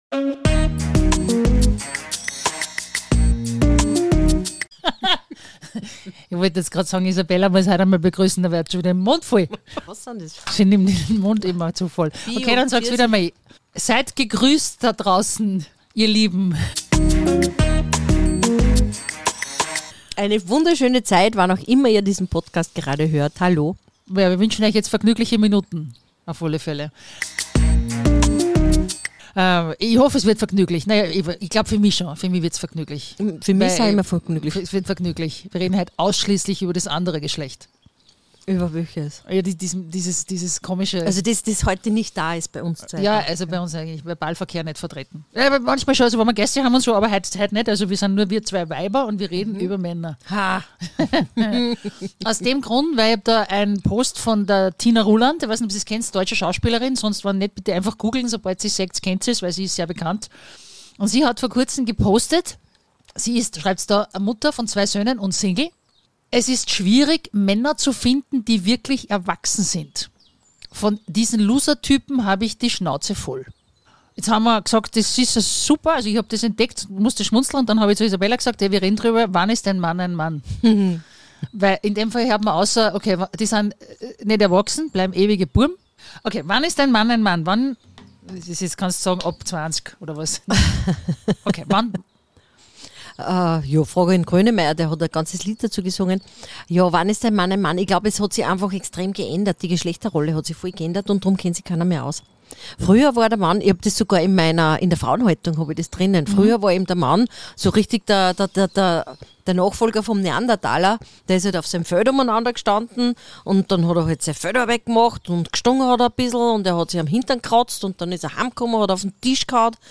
Eine ernüchternde Podcast-Folge mit viel weiblichem Humor und Instinkt für das andere Geschlecht.